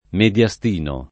vai all'elenco alfabetico delle voci ingrandisci il carattere 100% rimpicciolisci il carattere stampa invia tramite posta elettronica codividi su Facebook mediastino [ med L a S t & no ; meno bene med L#S tino ] s. m. (med.)